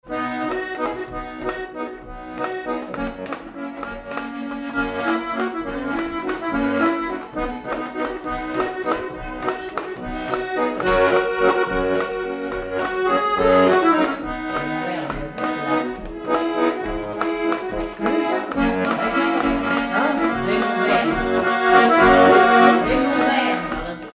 Danse
Villard-sur-Doron
circonstance : bal, dancerie
Pièce musicale inédite